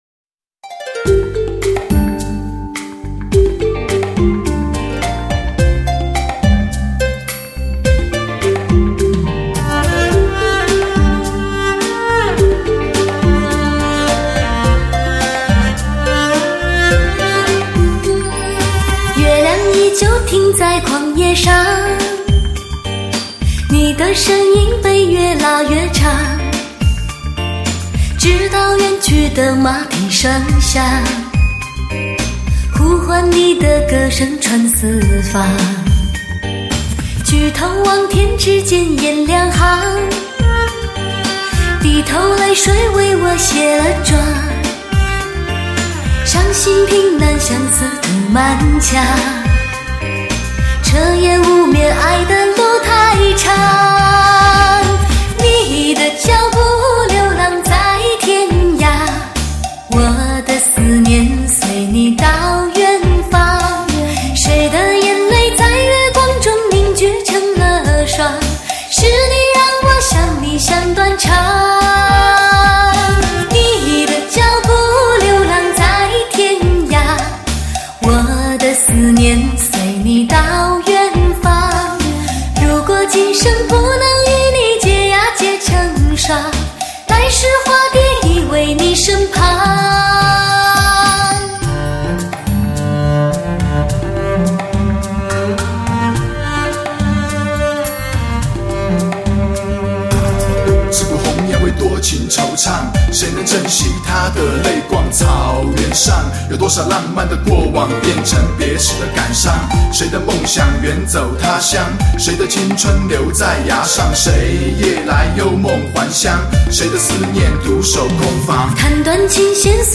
专辑格式：DTS-CD-5.1声道
绝佳的录音技术不容置疑 极具声色感染力的发烧音效